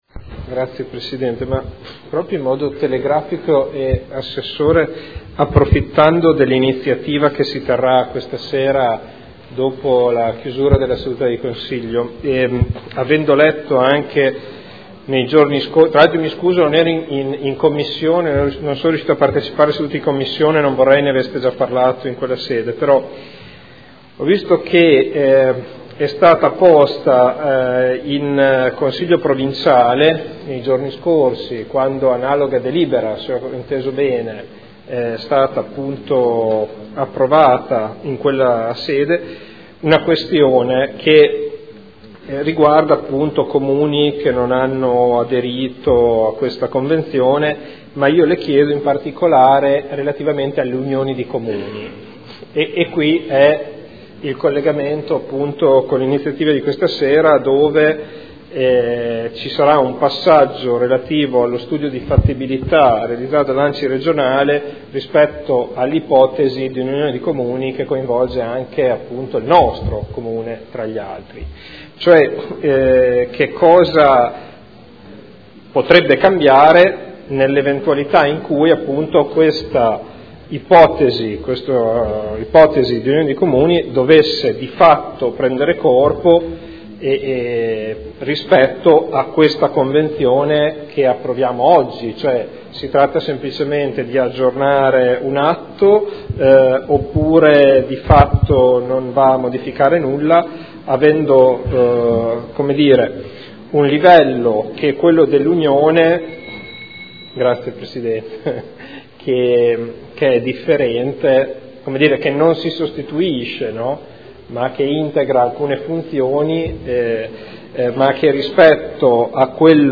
Seduta del 16 gennaio. Proposta di deliberazione: Convenzione per l’utilizzo del Difensore Civico Territoriale – Approvazione. Dibattito